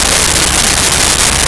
Car Engine Run 01
Car Engine Run 01.wav